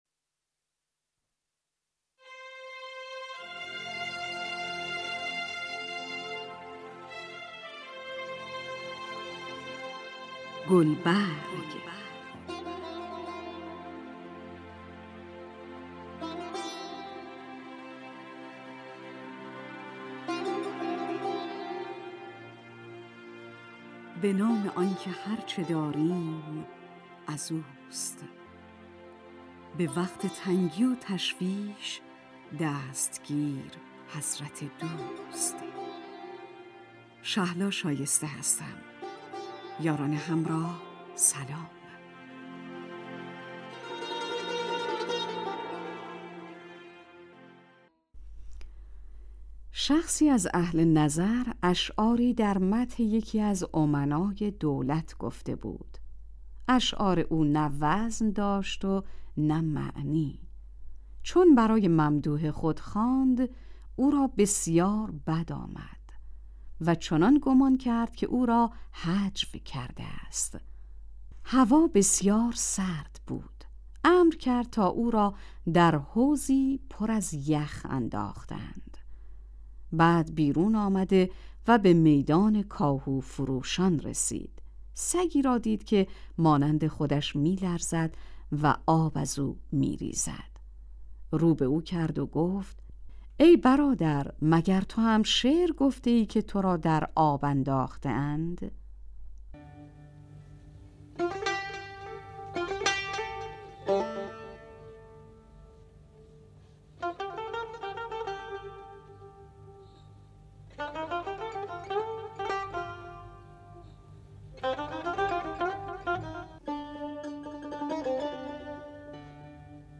برنامه ای ادبی و فرهنگی است که گوشه چشمی بر حکایات و اشعار پندآموز متون مشاهیر شعر و ادب دارد.